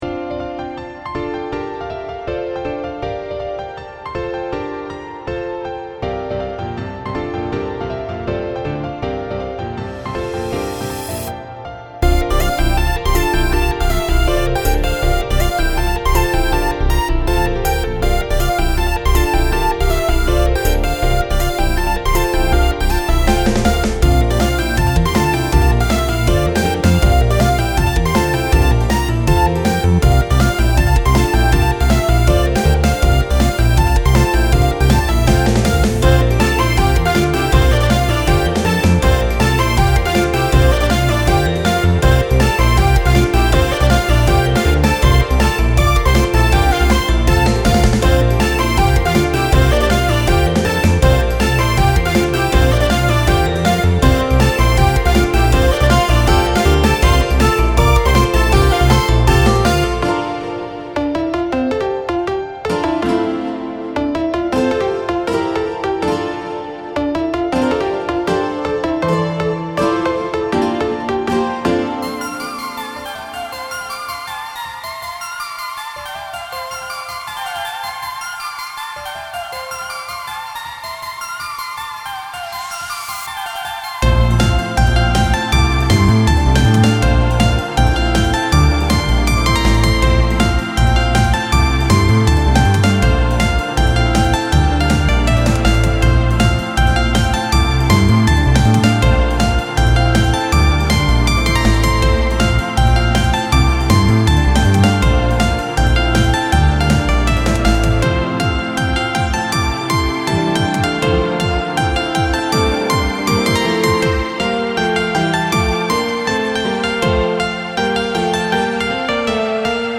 It turned out to be a very bright melody.